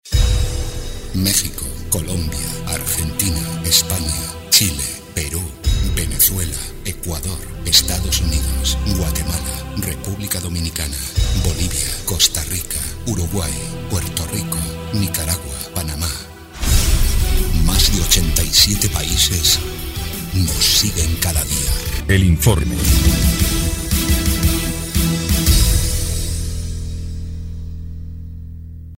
EL INFORME 4° Clip de Noticias del 1 de abril de 2026